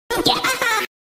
Bf Laugh